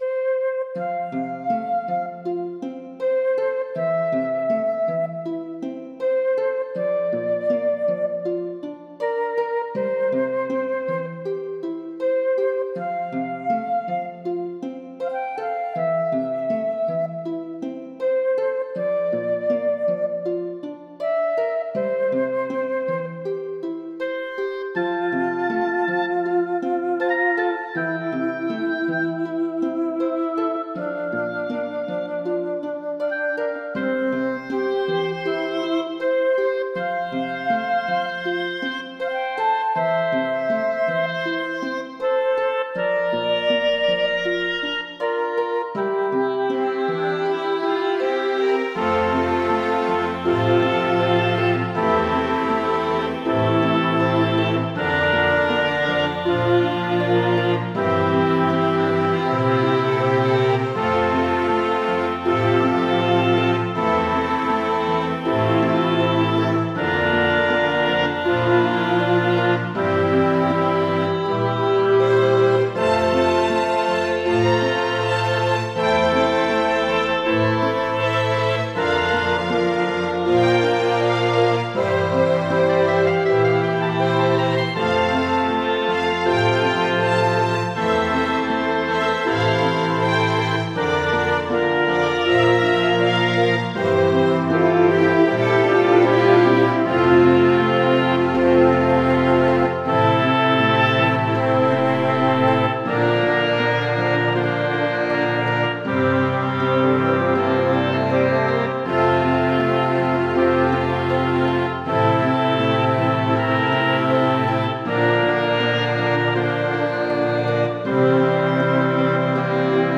versione per orchestra virtuale (pan)